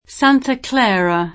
vai all'elenco alfabetico delle voci ingrandisci il carattere 100% rimpicciolisci il carattere stampa invia tramite posta elettronica codividi su Facebook Santa Clara [ S# nta kl # ra ; sp. S# nta kl # ra ] top. (Am.) — anche con pn. ingl. [ S ä ^ ntë kl $ ërë ] come nome di vari luoghi degli Stati Uniti